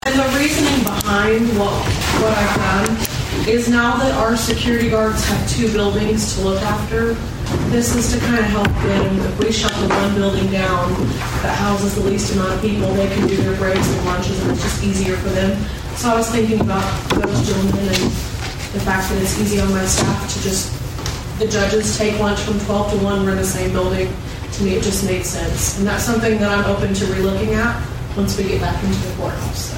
Court Clerk Lavendar Carroll explains why her department will begin